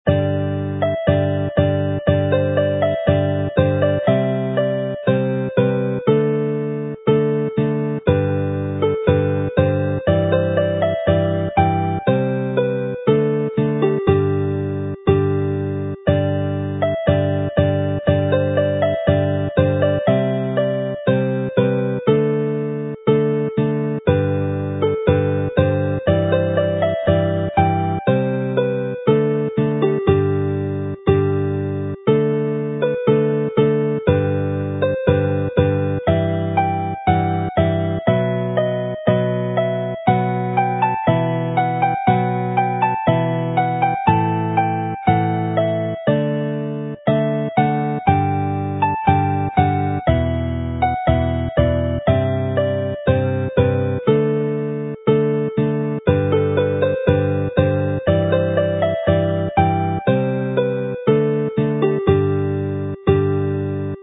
mp3 file, slow with chords